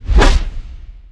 minotaur_swish.wav